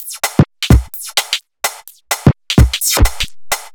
Index of /musicradar/uk-garage-samples/128bpm Lines n Loops/Beats
GA_BeatFiltC128-06.wav